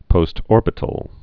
(pōst-ôrbĭ-tl)